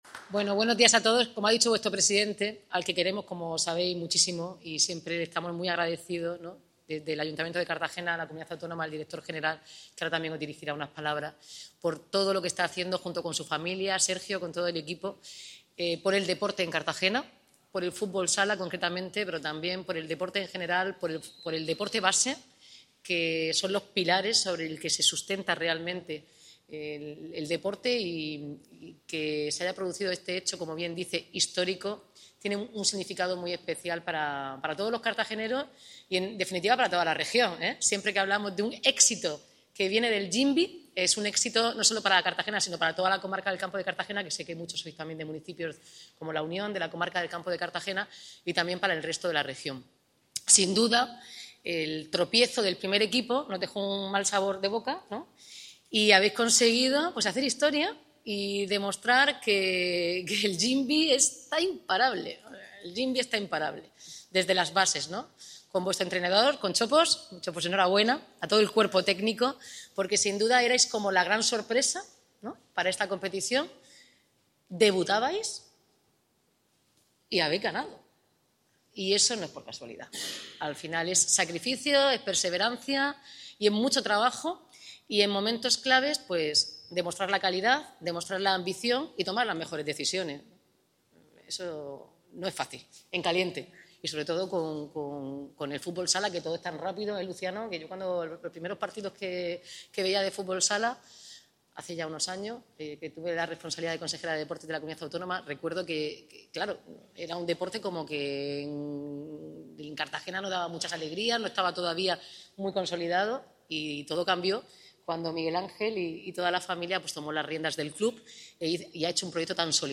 La alcaldesa ha recibido al equipo y cuerpo t�cnico en el Palacio Consistorial tras ganar este domingo la final de la Copa, disputada en Granada, frente al Entrerr�os Automatizaci�n Zaragoza
Audio: Recepci�n de la alcaldesa al equipo juvenil del Jimbee tras ganar la Copa de Espa�a (MP3 - 6,39 MB)